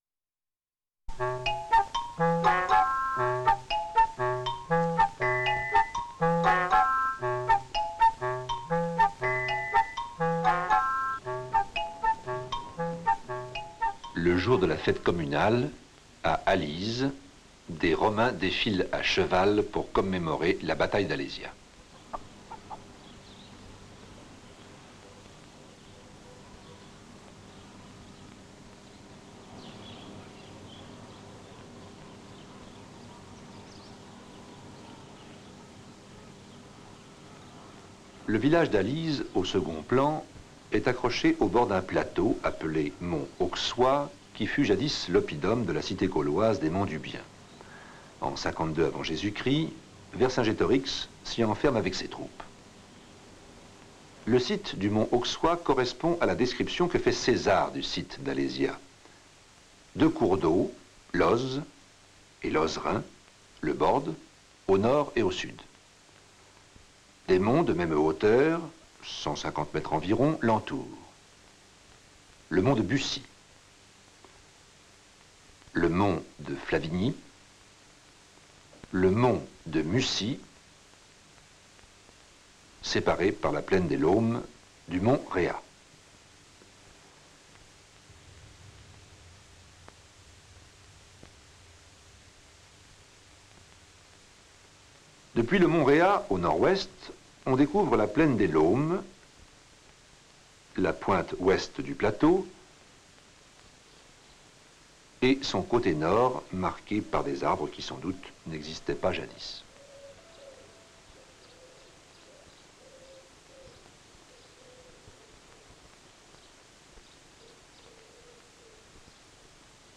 Trois chercheurs racontent la tragique épopée du héros Vercingétorix contre César et ses armées. Le récit débute par les événements qui ont incité César à intervenir en Gaule et se poursuit par la rébellion gauloise contre Rome et le choix de Vercingétorix comme chef libérateur.